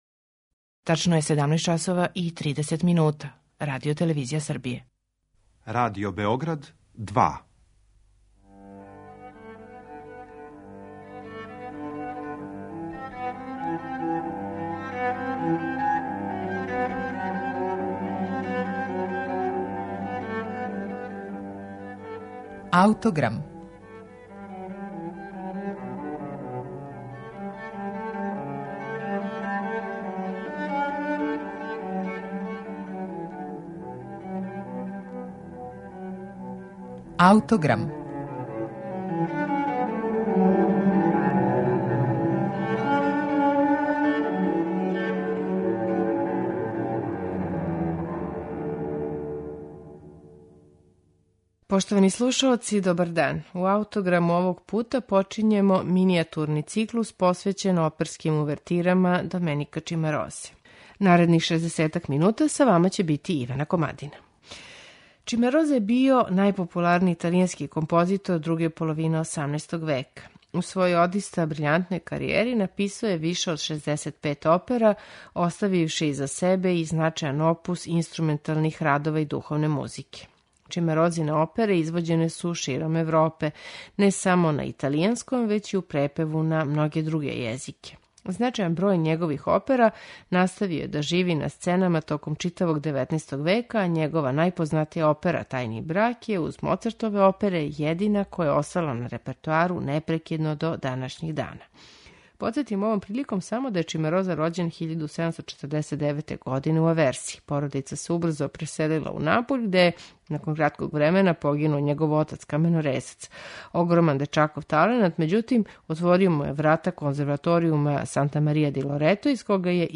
Оперске увертире Доменика Ћимарозе
почињемо минијатурни циклус посвећен Чимарозиним оперским увертирама.Слушаћете их у интерпретацији оркестра Nikolaus Esterhazi Sinfonia